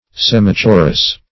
Search Result for " semichorus" : The Collaborative International Dictionary of English v.0.48: Semichorus \Sem"i*cho`rus\, n. (Mus.) A half chorus; a passage to be sung by a selected portion of the voices, as the female voices only, in contrast with the full choir.